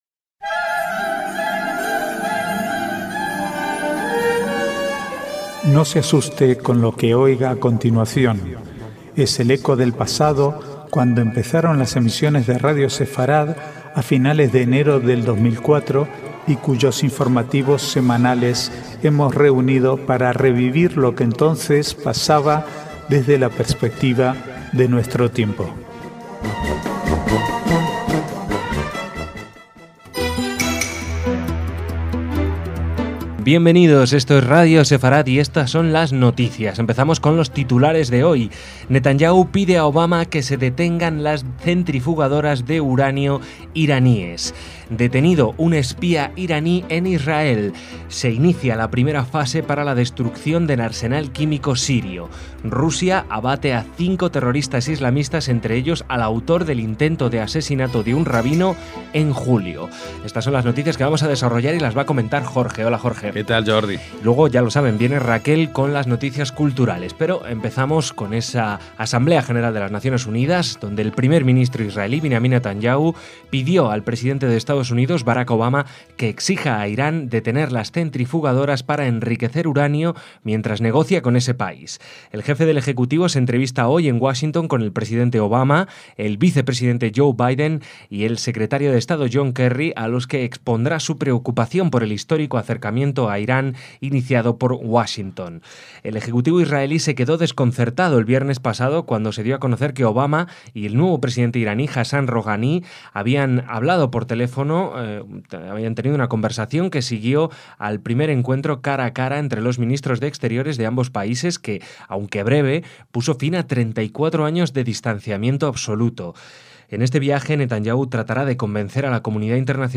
Archivo de noticias del 1 al 4/10/2013